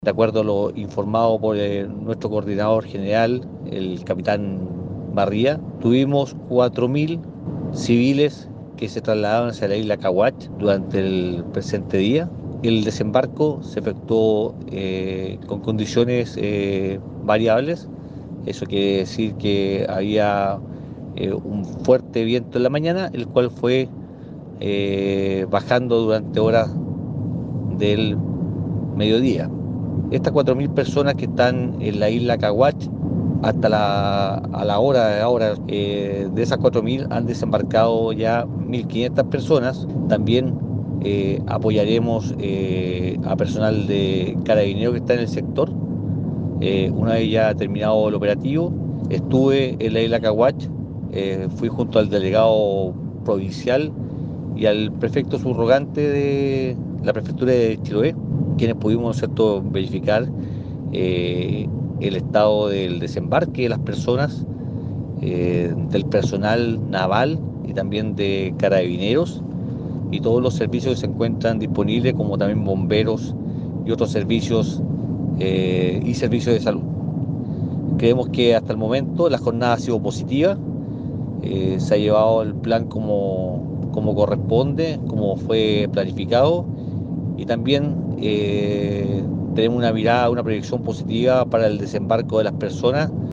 En cuanto a los operativos de seguridad desplegados por la Armada para otorgar viabilidad a las embarcaciones que desde distintos puntos navegaron hacia Caguach, se refirió el gobernador marítimo de Chiloé, capitán de corbeta Ricardo Cárcamo.